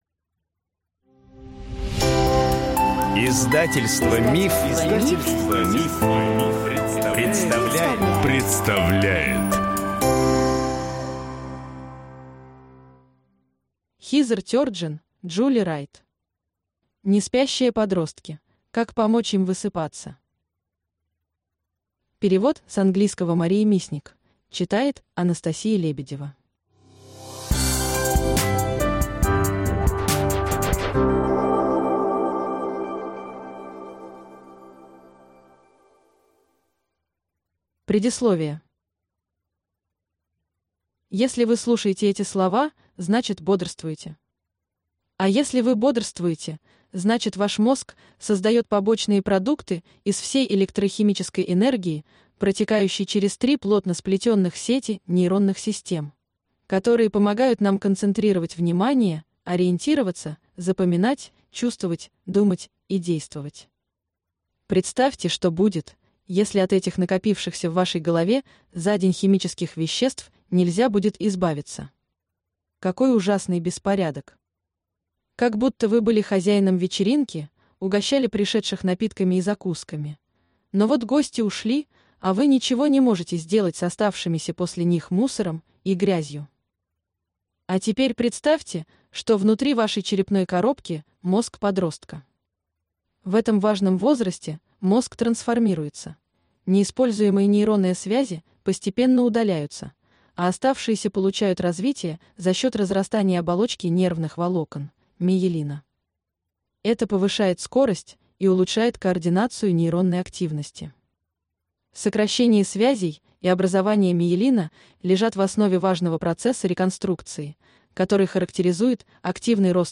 Аудиокнига Неспящие подростки. Как помочь им высыпаться | Библиотека аудиокниг